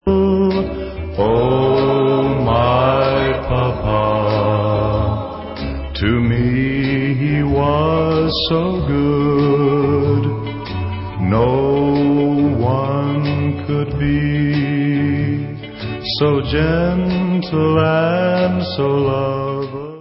sledovat novinky v oddělení Rock & Roll